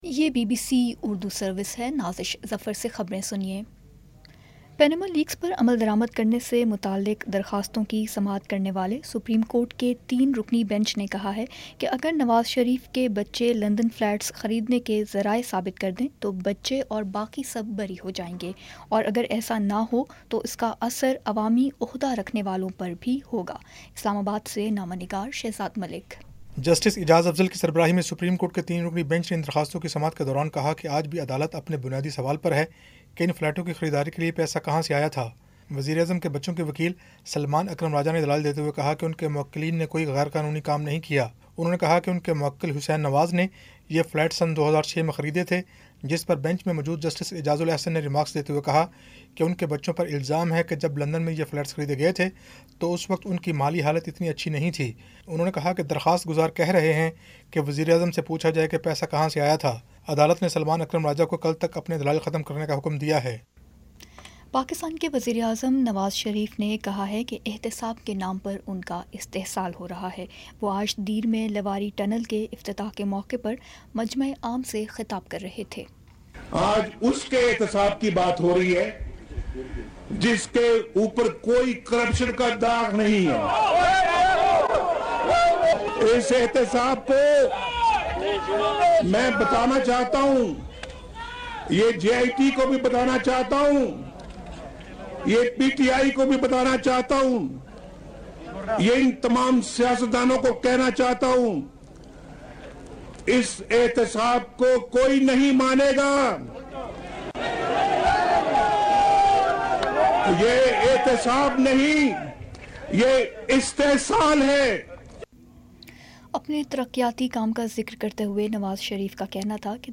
جولائی 20 : شام پانچ بجے کا نیوز بُلیٹن